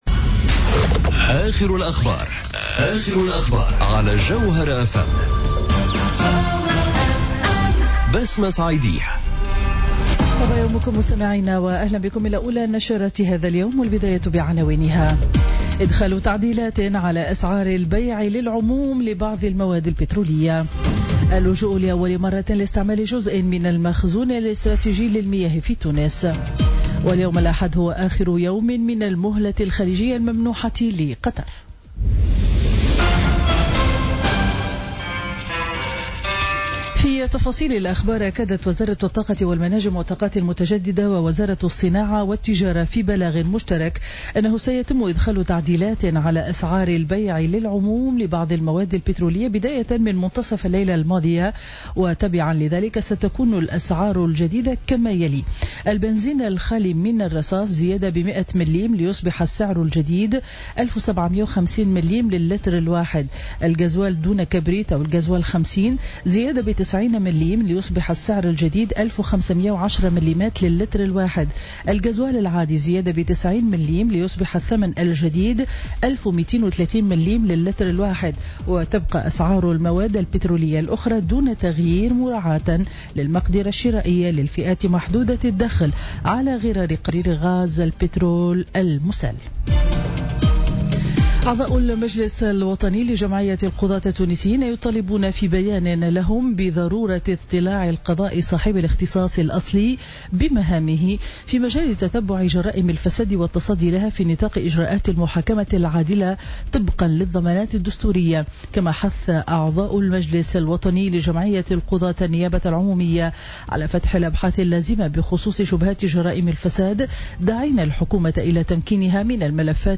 شرة أخبار السابعة صباحا ليوم الأحد 2 جويلية 2017